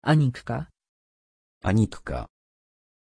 Aussprache von Anikka
pronunciation-anikka-pl.mp3